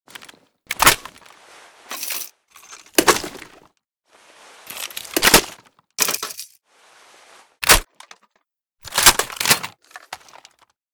pkm_reload.ogg